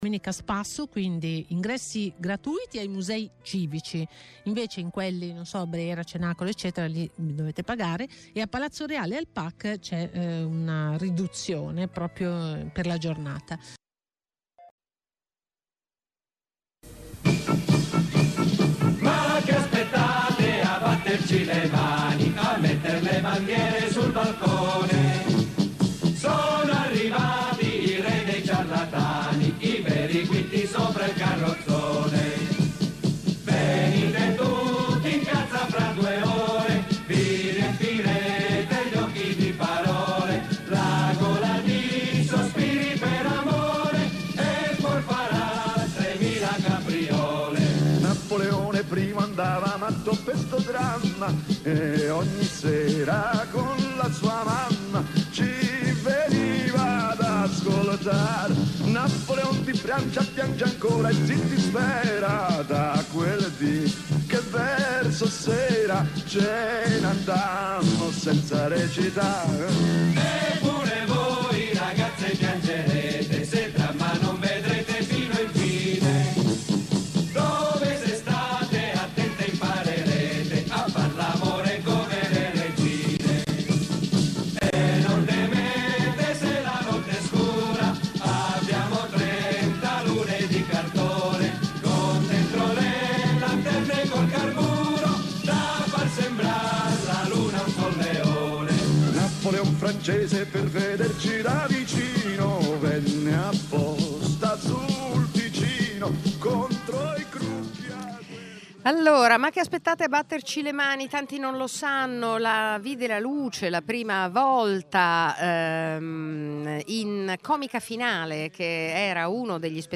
Nella tappa del tour “Falla con noi” di Radio Popolare ci siamo trasferiti con tante trasmissioni alla Palazzina Liberty in occasione del...